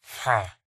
mob / villager / no3.ogg